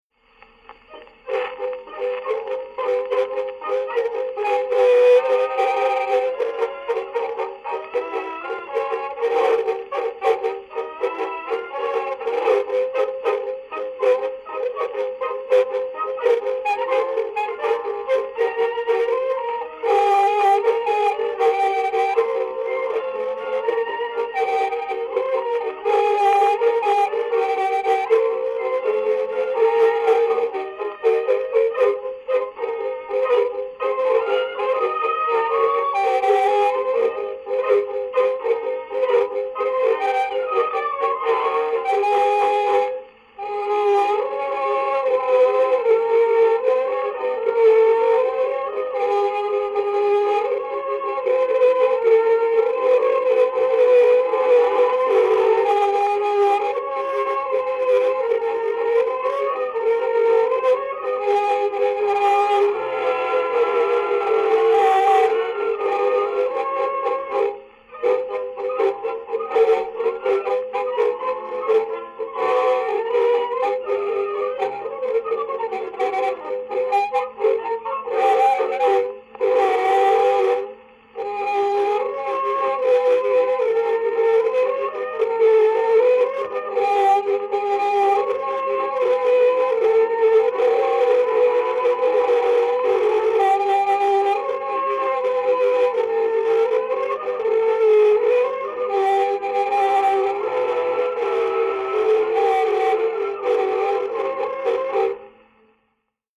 Streich Orchester, Artiphon